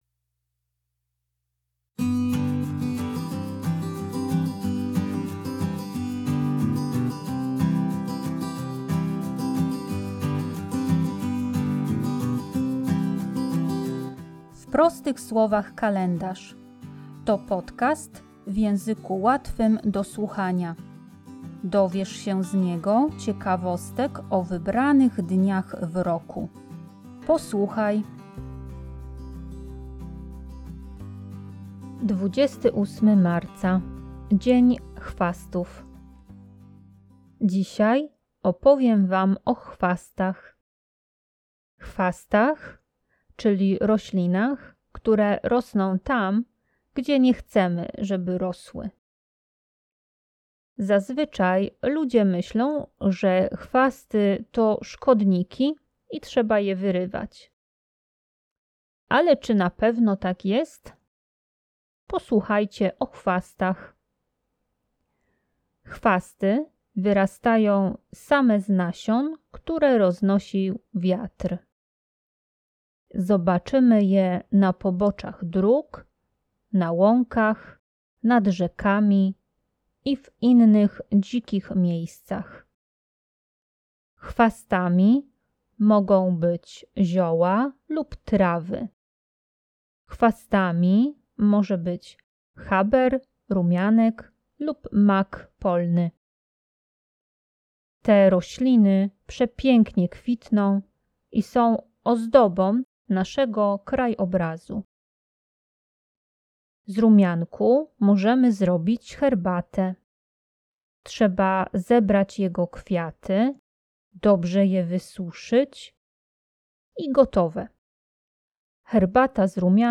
W podcaście usłyszycie dźwięki kroków osoby idącej wśród chwastów.